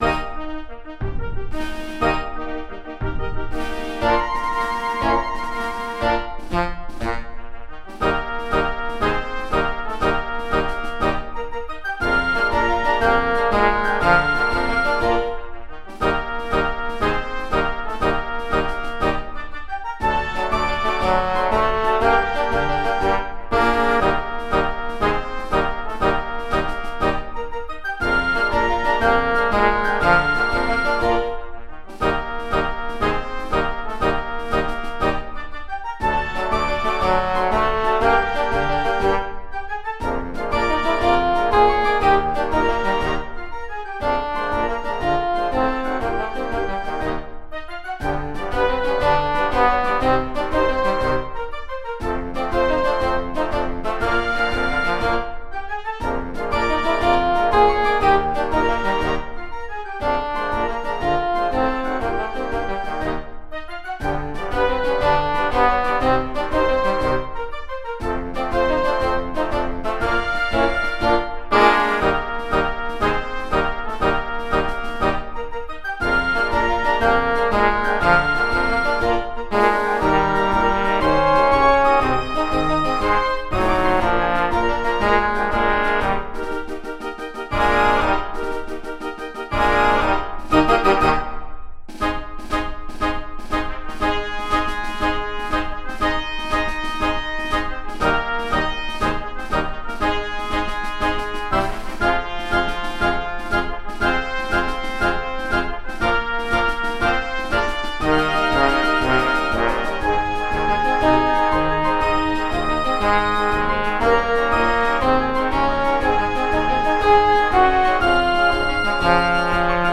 Marce
m. d’entrata